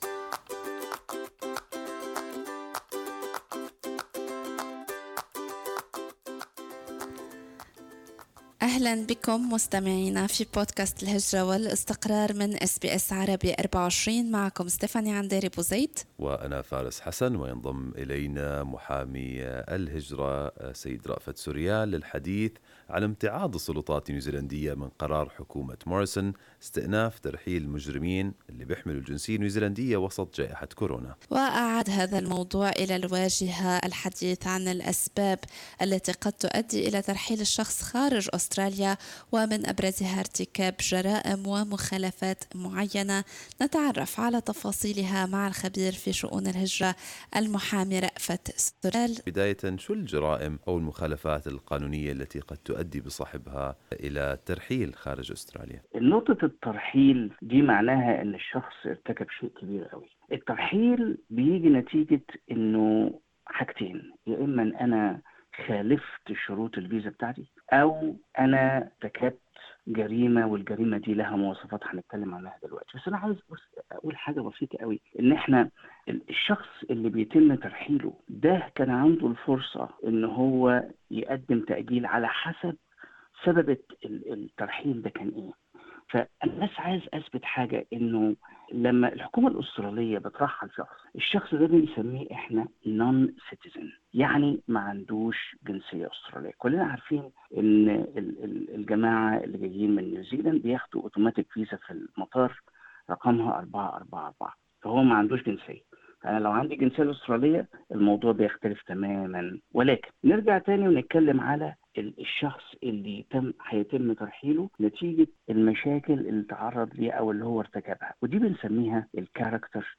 تعرفوا على تفاصيل قوانين الترحيل في أستراليا في لقاء مع خبير الهجرة المحامي